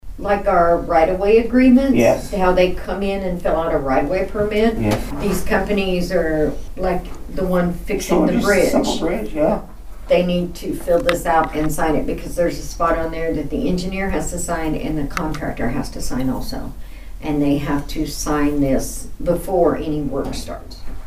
The Nowata County Commissioners met for a regularly scheduled meeting at the Nowata County Annex on Monday morning.
County Clerk Kay Spurgeon detailed the agreement's purpose.